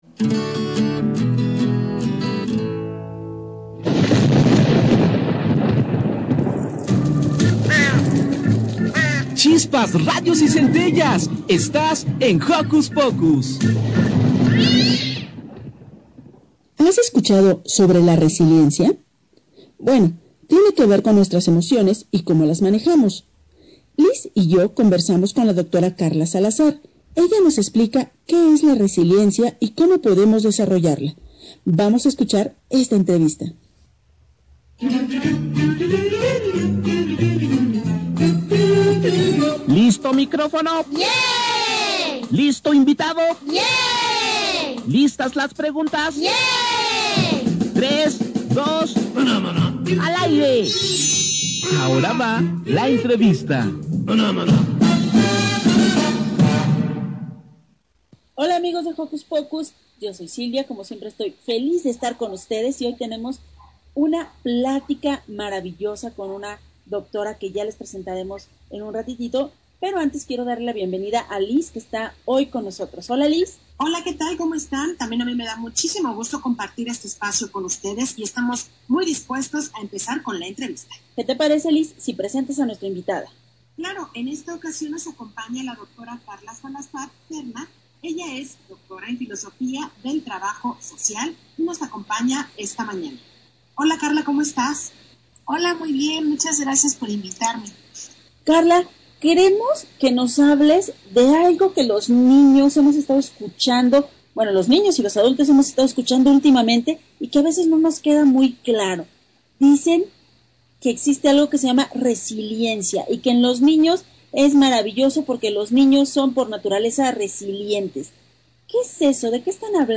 Radio UNAM: Jocus Pocus. Resiliencia y COVID-19 para peques y no tan peques, entrevista